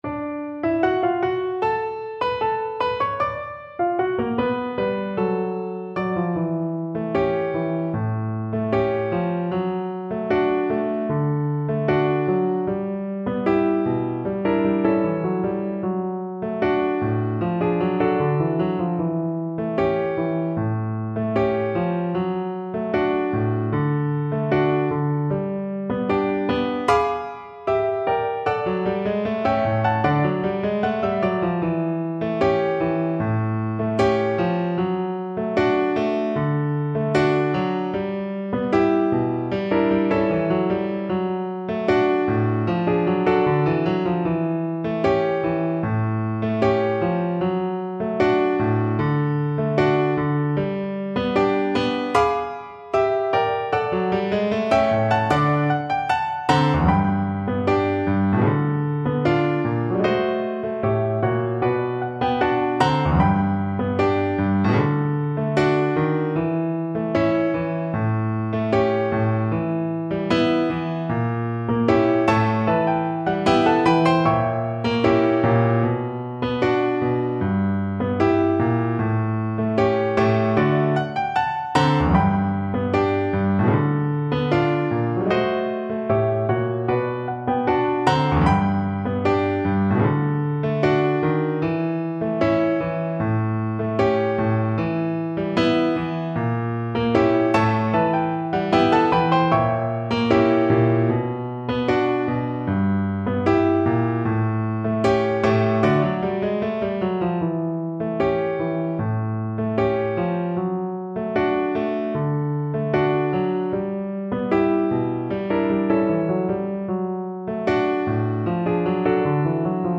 Free Sheet music for Viola
ViolaPianoViola (8va)
D major (Sounding Pitch) (View more D major Music for Viola )
2/4 (View more 2/4 Music)
Very slow march time = 76 Very slow march time
Jazz (View more Jazz Viola Music)